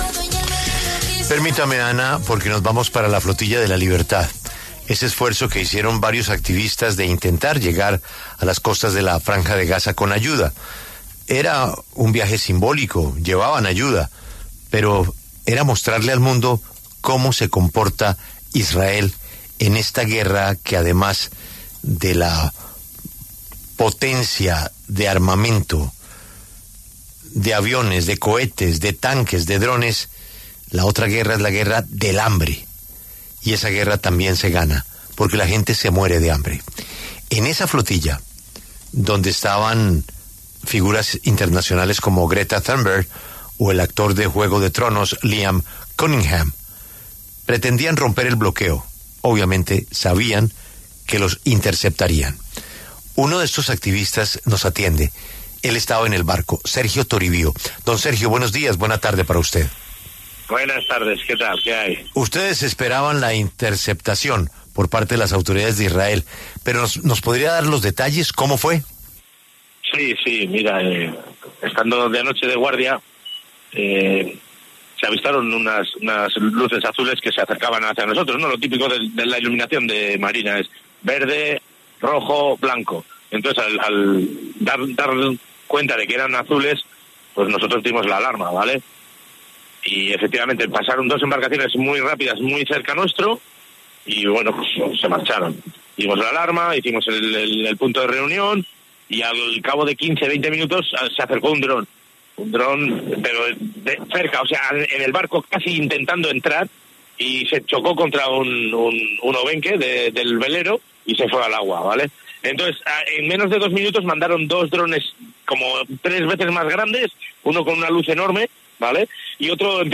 El activista español de la ‘Flotilla de la Libertad’